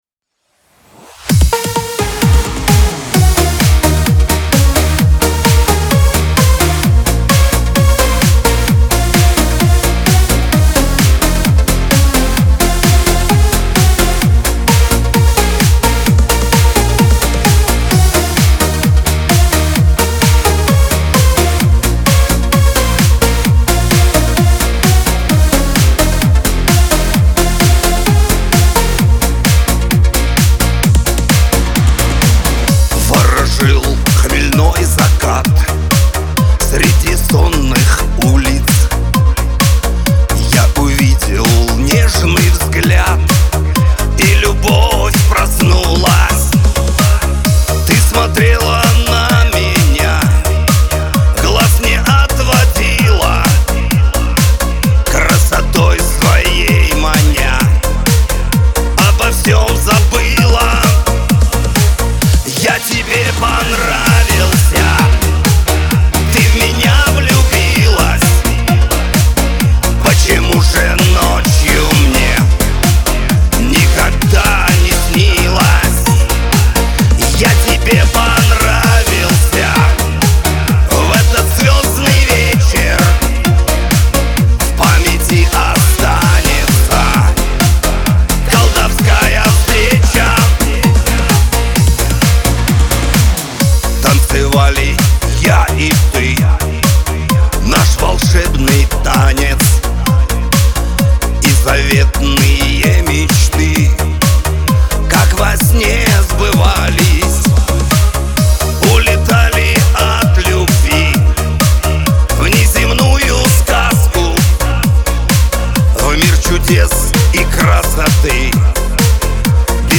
Лирика
Шансон